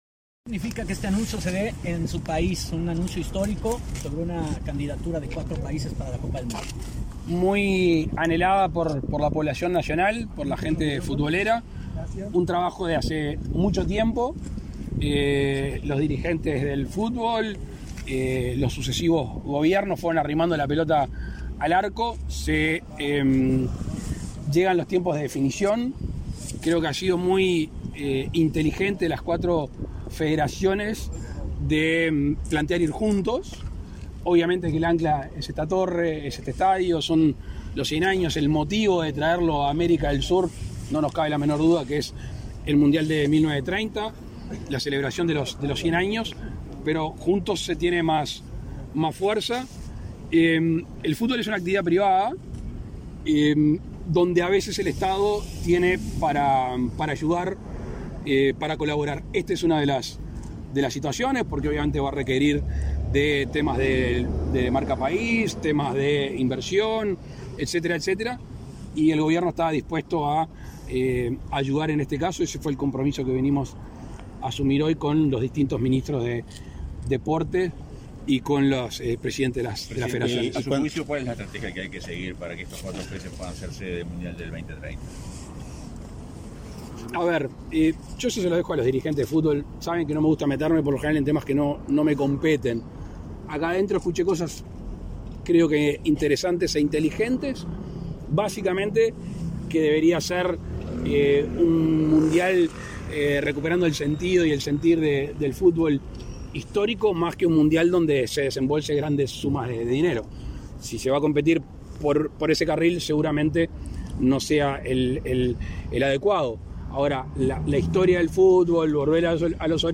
Declaraciones a la prensa del presidente de la República, Luis Lacalle Pou
Tras participar en el lanzamiento de la candidatura de Argentina, Paraguay, Chile y Uruguay como países organizadores de la Copa Mundial de la FIFA